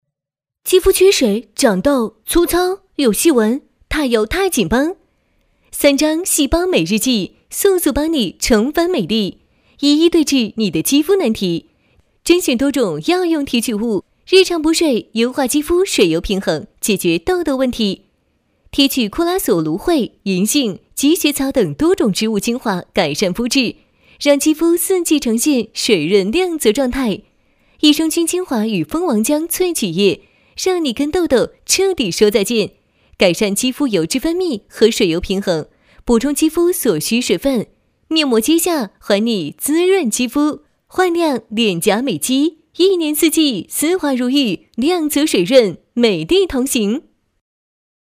女3号